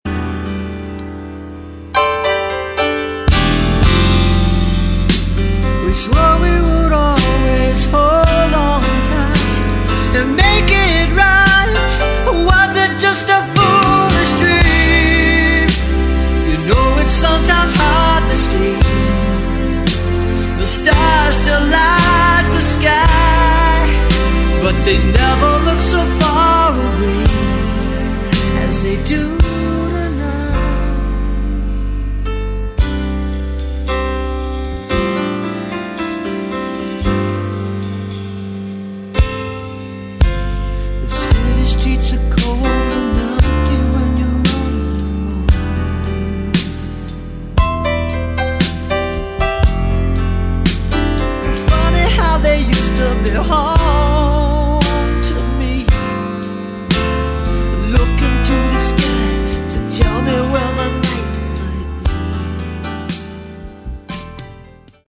Category: Melodic Rock
lead vocals
keyboards
guitars
bass
drums